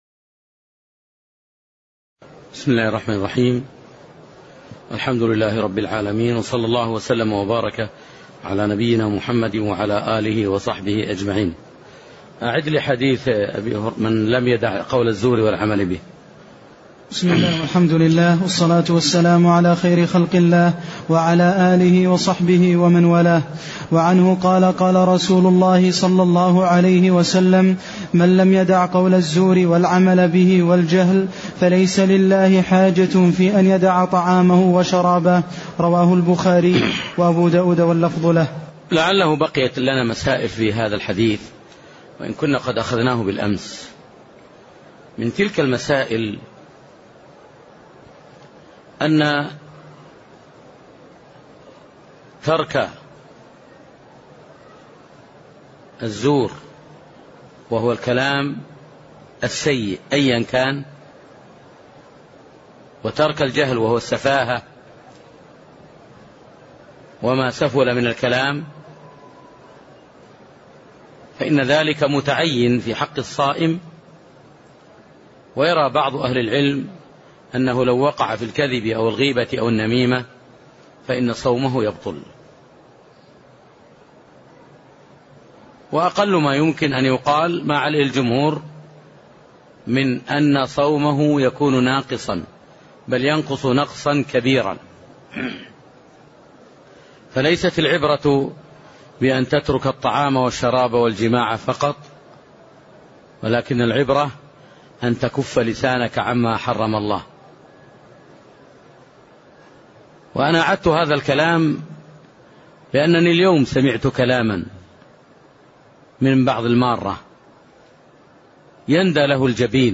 تاريخ النشر ٤ رمضان ١٤٢٨ هـ المكان: المسجد النبوي الشيخ